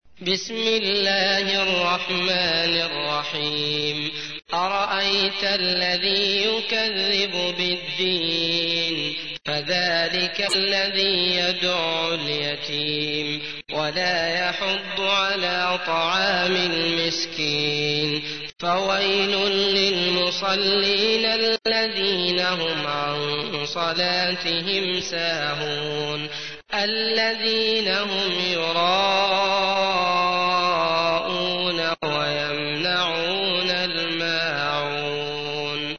تحميل : 107. سورة الماعون / القارئ عبد الله المطرود / القرآن الكريم / موقع يا حسين